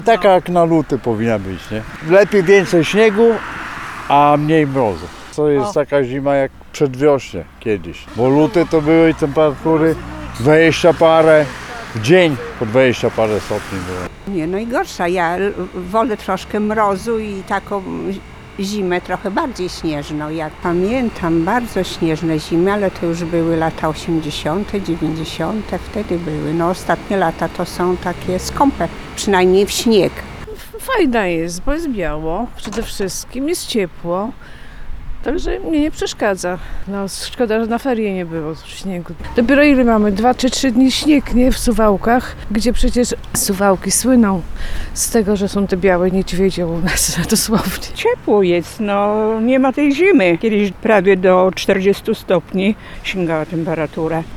Aura śniegu i niższych temperatur to coś, za czym tęsknili mieszkańcy, których spotkaliśmy na ulicach Suwałk. Niektórzy uważają, że jak na tę porę roku jest ciepło, a inni z utęsknieniem wspominają dawne zimy, kiedy temperatury sięgały nawet – 40 stopni Celsjusza.
zima-sonda-18.02.mp3